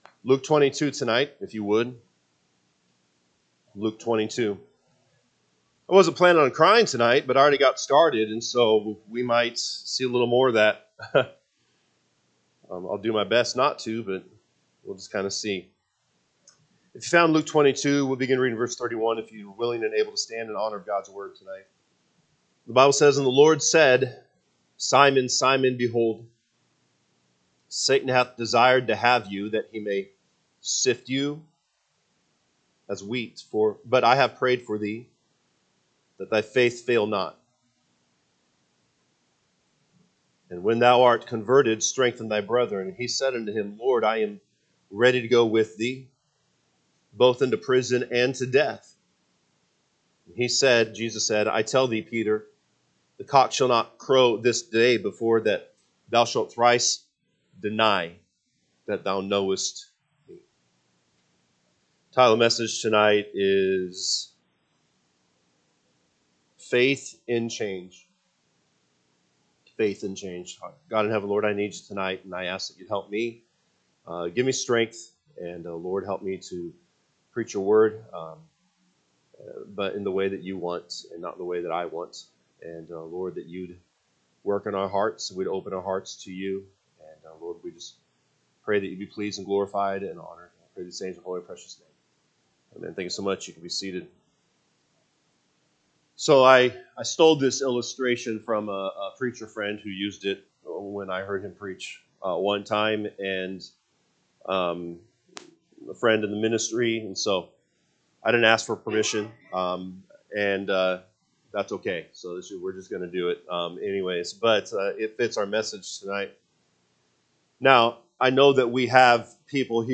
May 18, 2025 pm Service Luke 22:31-34 (KJB) 31 And the Lord said, Simon, Simon, behold, Satan hath desired to have you, that he may sift you as wheat: 32 But I have prayed for thee, that …